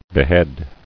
[be·head]